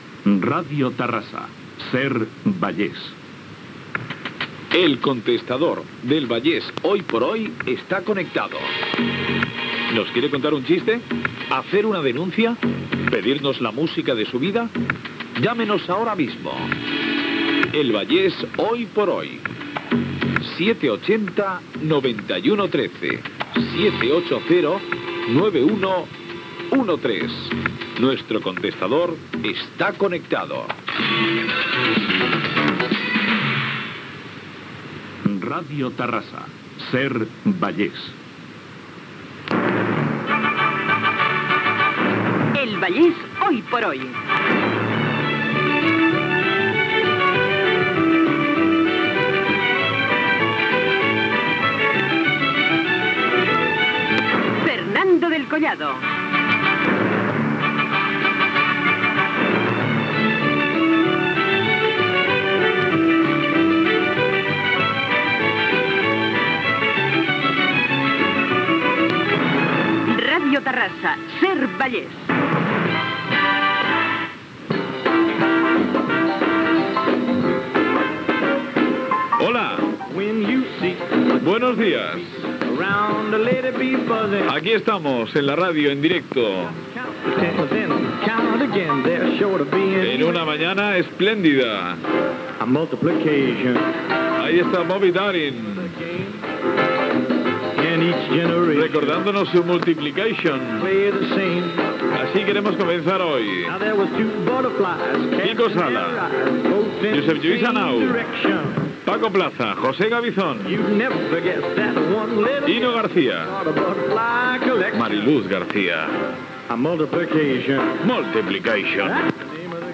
"El contestador de "El Vallés hoy por yoy", indicatiu de l'emissora, careta del programa, salutació inicial amb els noms de l'equip i tema musical
Entreteniment